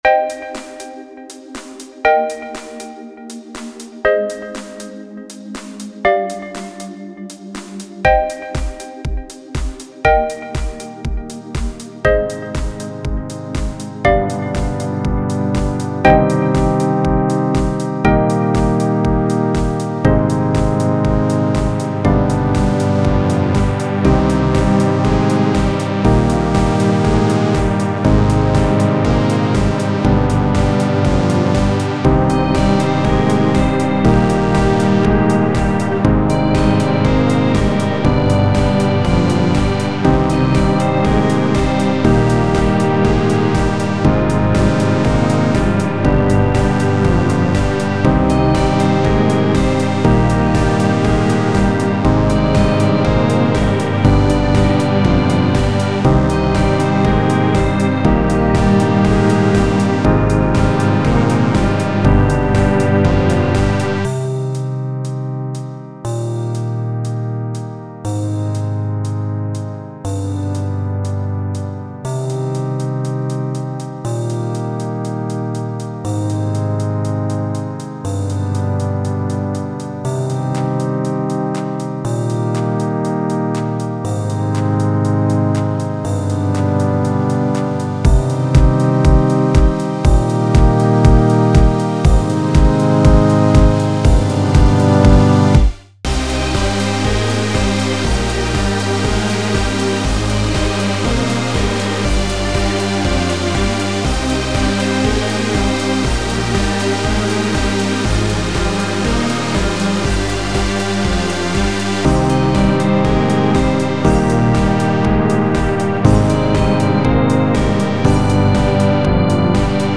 This morning i opened up Ableton and told myself i was going to make something.
Today, i decided to just… make something extremely simple. It sounds childish and silly, but… i actually did it, and it’s pretty listenable. i think that’s progress.